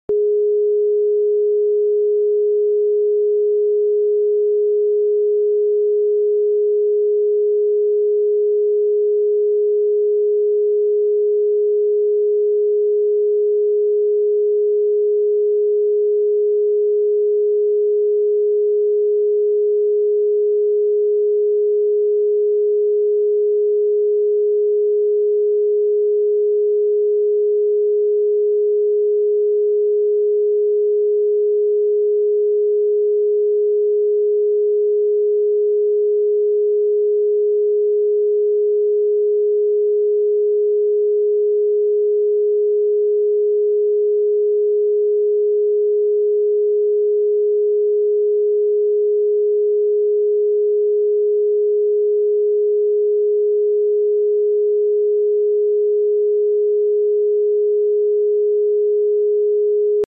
It’s time to reset your energy. 417 Hz is known as the frequency of transformation and undoing negative energy. It helps clear emotional blockages, remove subconscious fears, and cleanse past trauma from your energetic field. Listening to this frequency promotes: 🔹 Inner healing 🔹 Emotional release 🔹 Fresh starts and new beginnings 🔹 A deep feeling of peace and flow Let it wash over you like a wave — gently pushing out what no longer serves you.